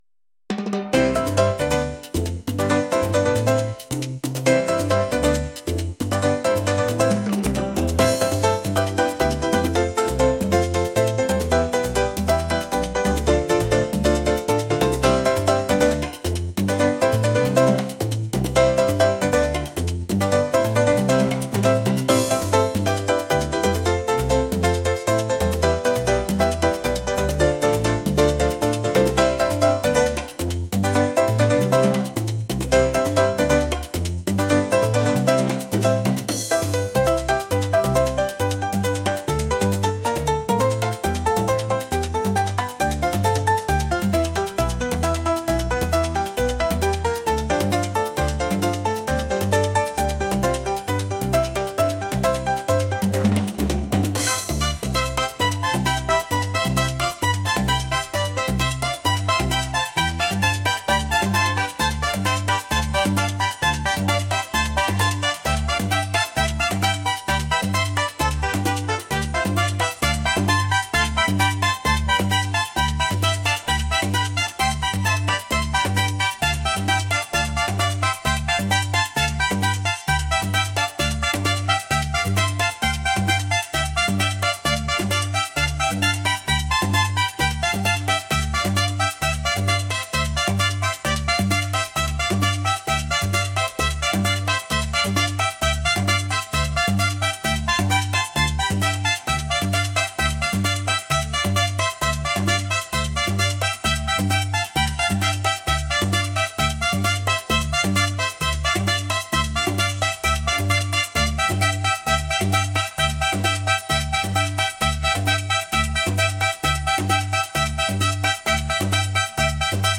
latin | energetic